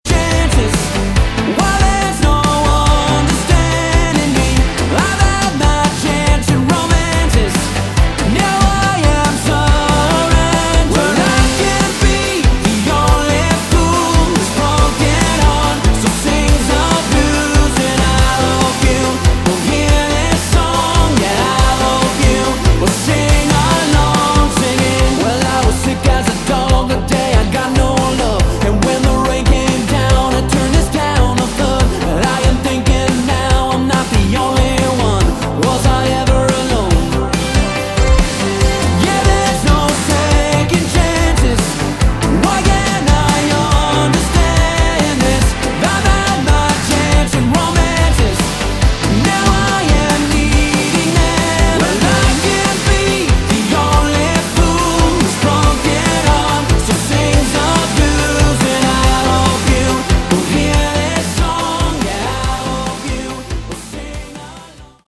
Category: AOR
Vocals, Guitars, Synths
Drums, Percussion